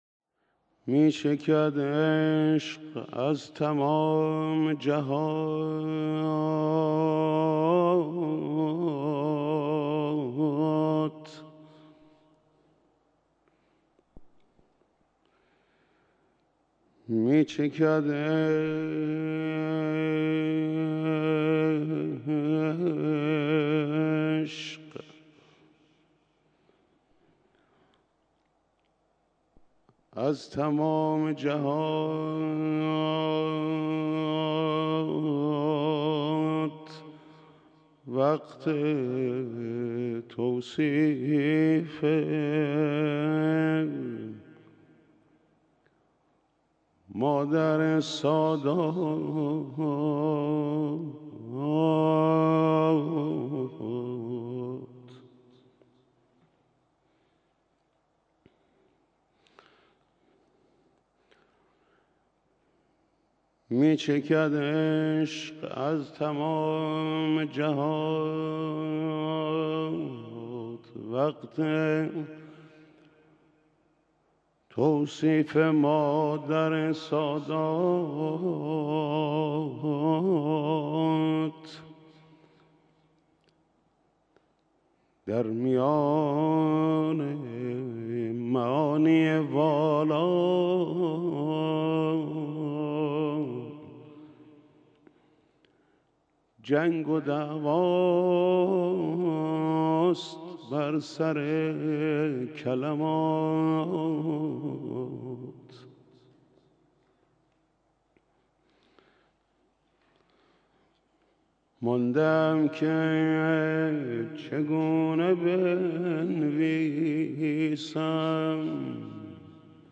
مراسم عزاداری شب شهادت حضرت فاطمه زهرا سلام‌الله‌علیها
مداحی آقای محمود کریمی